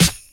Tuned drums (F key) Free sound effects and audio clips
• Short Acoustic Snare Sound F Key 388.wav
Royality free snare sample tuned to the F note. Loudest frequency: 3824Hz
short-acoustic-snare-sound-f-key-388-iAY.wav